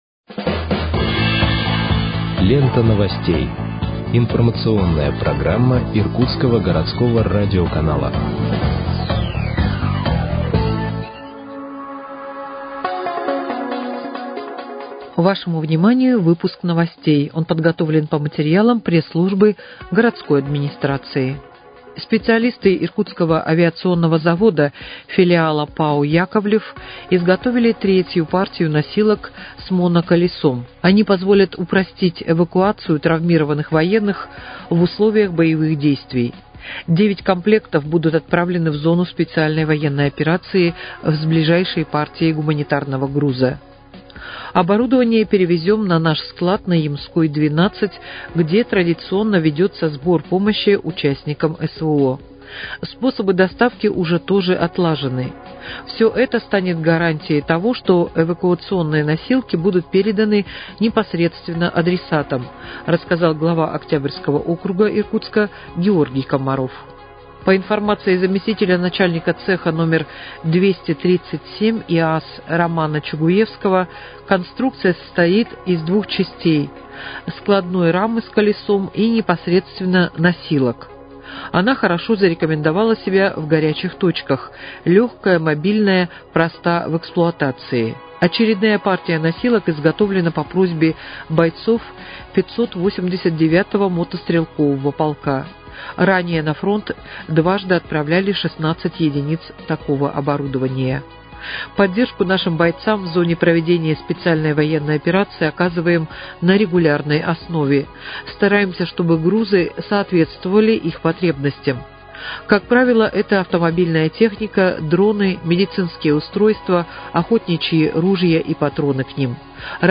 Выпуск новостей в подкастах газеты «Иркутск» от 6.03.2025 № 2